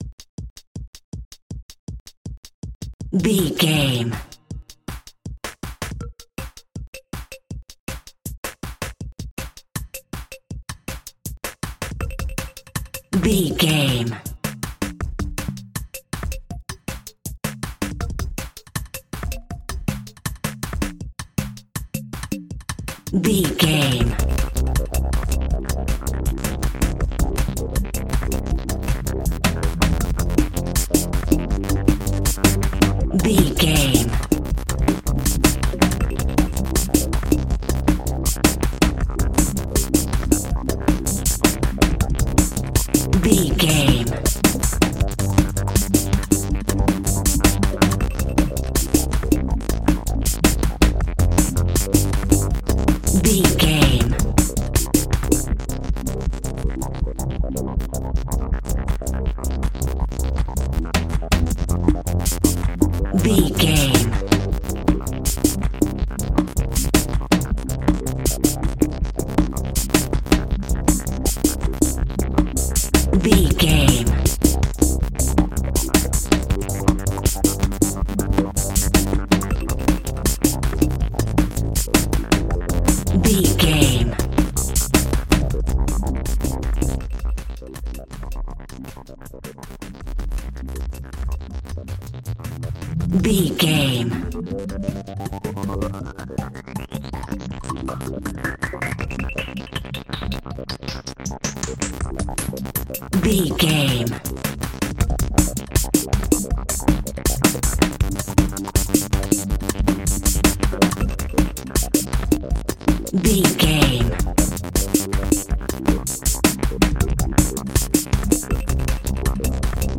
Ionian/Major
dark
energetic
driving
futuristic
suspense
drum machine
synthesiser
electro house
acid house music